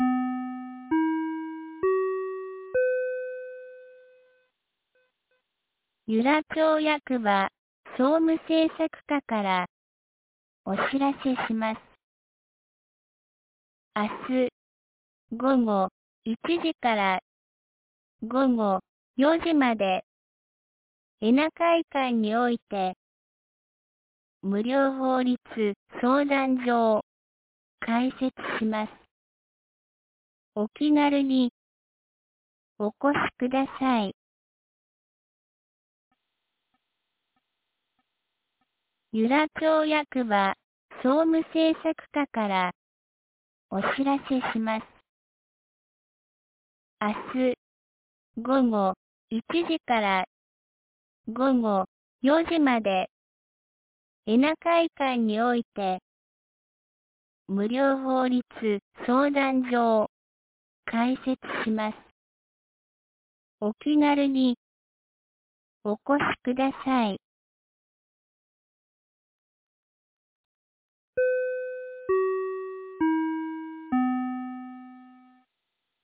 2025年08月06日 17時11分に、由良町から全地区へ放送がありました。